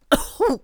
traf_damage2.wav